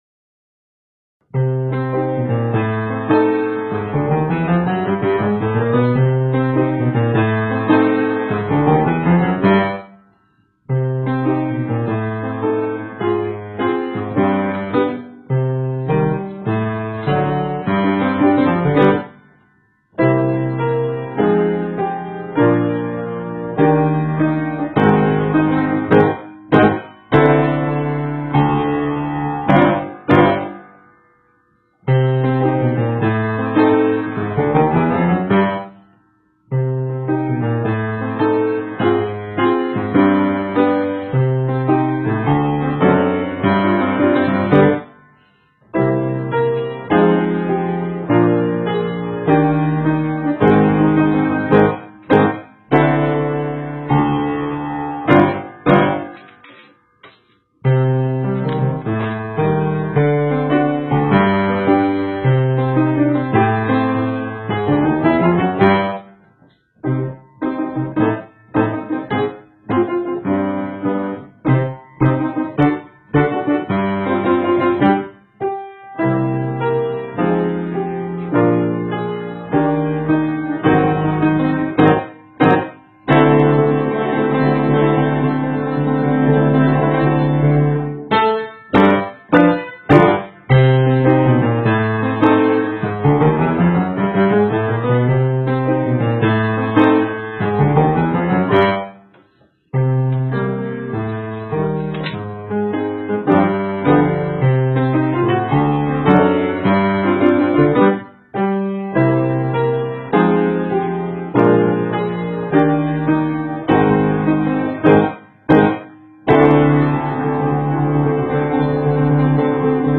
Grinch Accompaniment
Grinch_Accomp.m4a